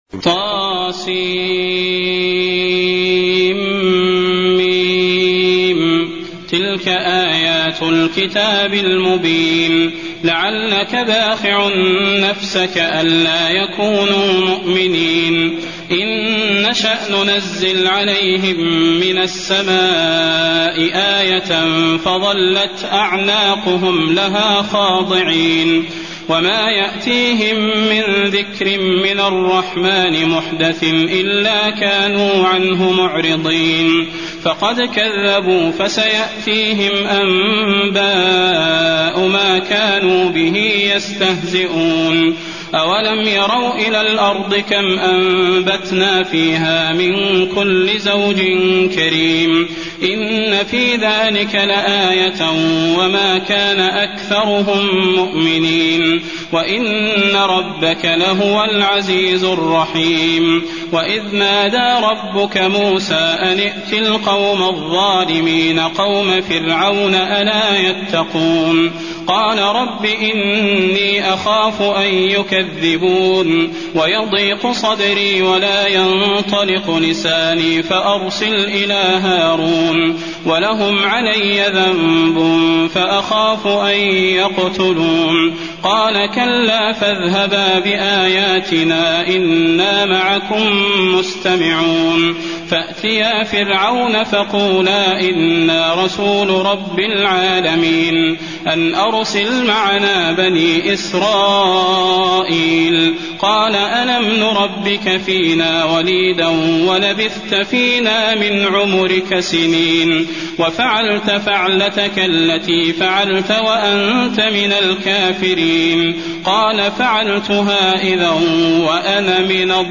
المكان: المسجد النبوي الشعراء